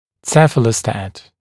[ˈsefələstæt][ˈсэфэлэстэт]цефалостат